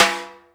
• Reggae Acoustic Snare Sample G# Key 23.wav
Royality free snare drum sample tuned to the G# note. Loudest frequency: 2675Hz
reggae-acoustic-snare-sample-g-sharp-key-23-uw6.wav